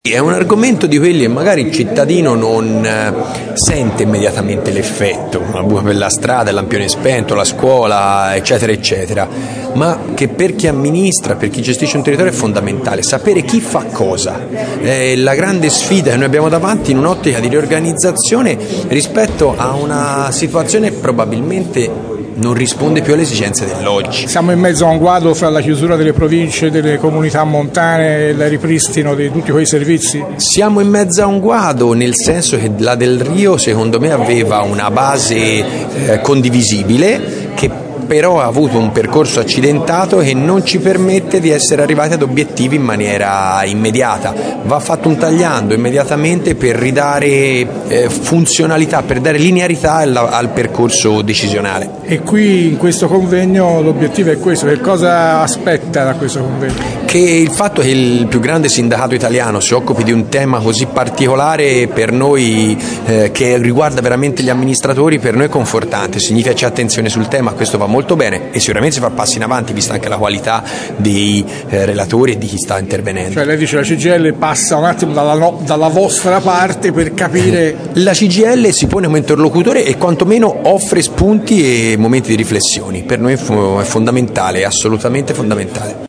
?Firenze, ‘Il disordinamento istituzionale’ questo è il titolo e il tema del convegno organizzato da Cgil Toscana, Ires Toscana e Fp Cgil Toscana che si svolto all’Auditorium al Duomo.